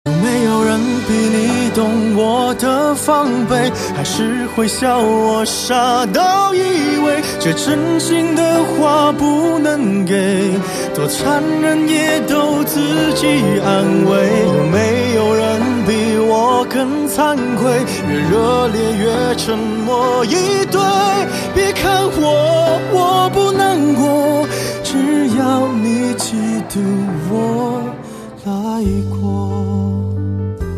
M4R铃声, MP3铃声, 华语歌曲 149 首发日期：2018-05-16 00:24 星期三